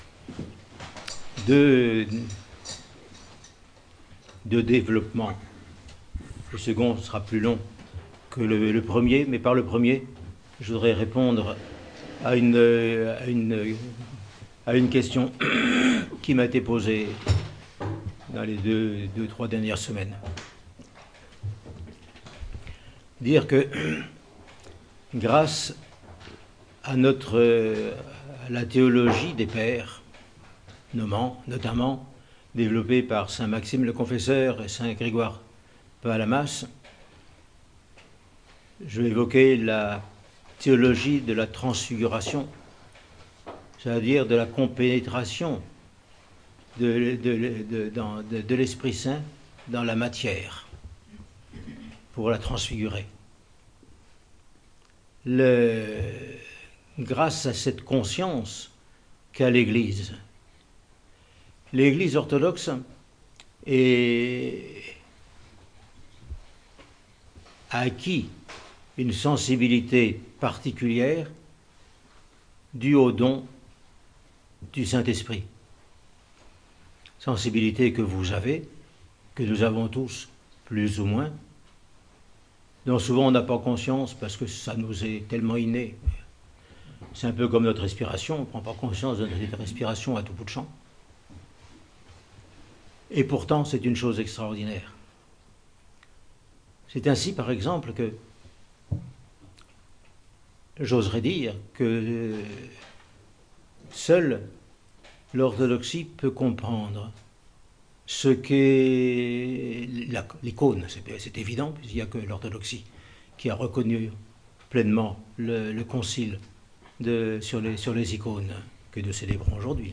Homélie sur le dimanche de l’Orthodoxie :Monastère de la Transfiguration